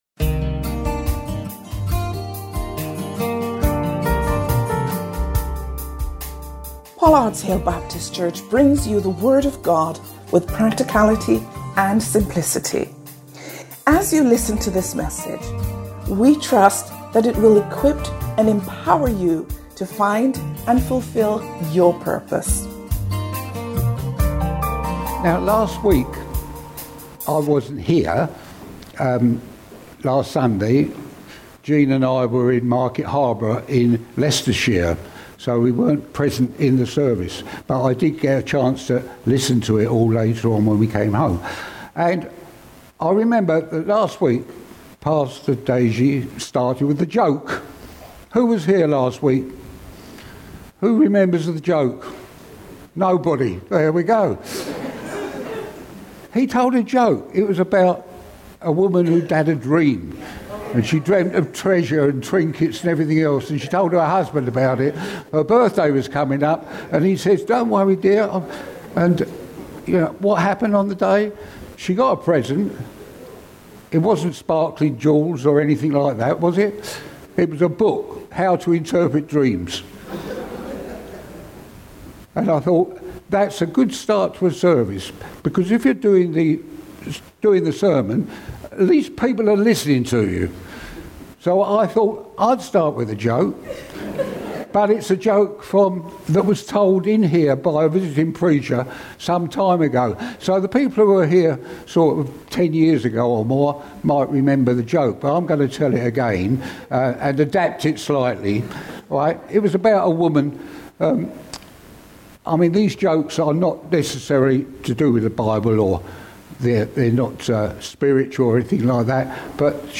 Life would be very different if all of our bills were marked as fully paid and we owed nothing to anyone. In this sermon series, we recognise that as Christians, our account with God does look like that, and we can move from debt to freedom because Jesus ensured that everything has been fully paid.